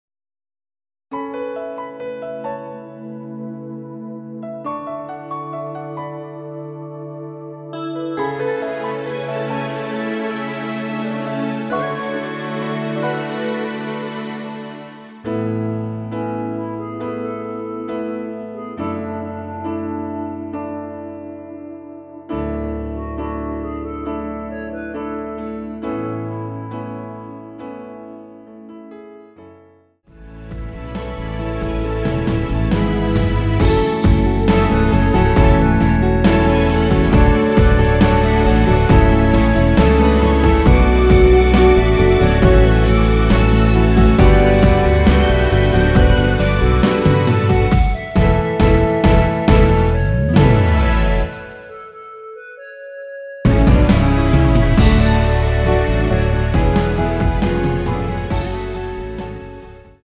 원키에서(-9)내린 멜로디 포함된 MR 입니다.(미리듣기 참조)
앞부분30초, 뒷부분30초씩 편집해서 올려 드리고 있습니다.
중간에 음이 끈어지고 다시 나오는 이유는